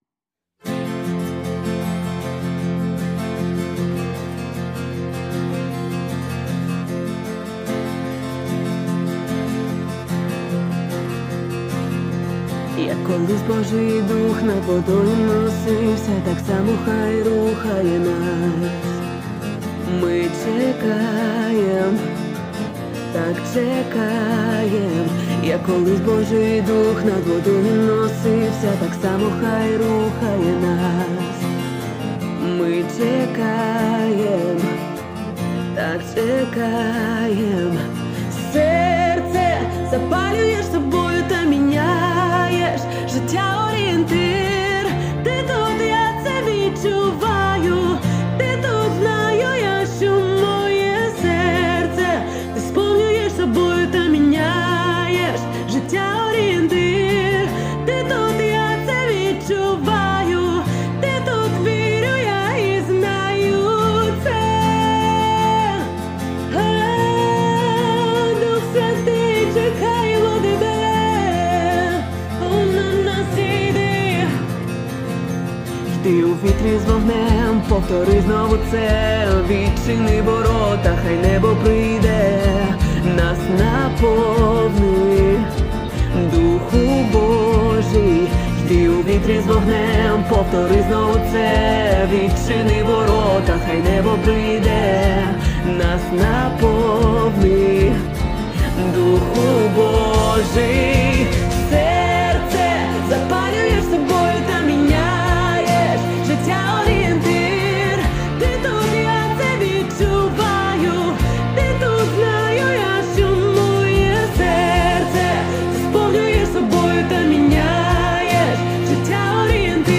1594 просмотра 777 прослушиваний 35 скачиваний BPM: 77